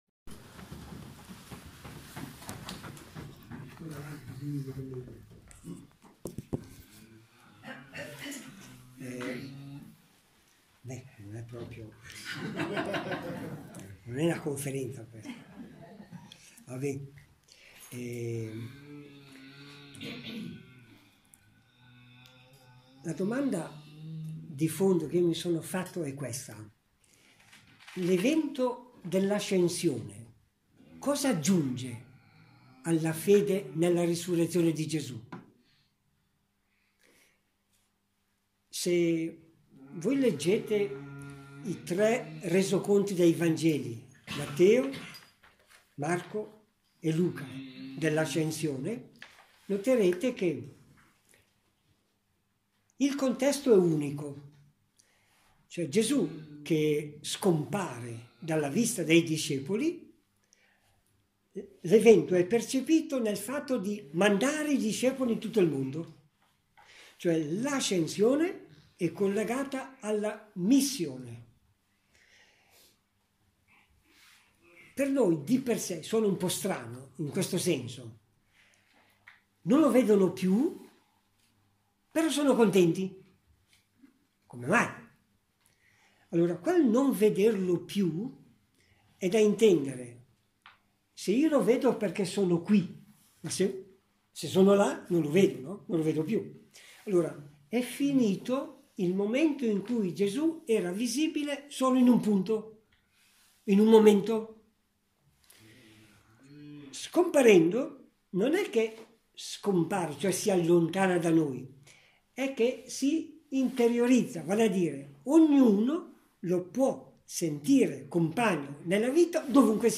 Audiovideo - Conferenza per i consacrati della Comunità Papa Giovanni XXIII. Villanova d'Asti, 13 maggio 2018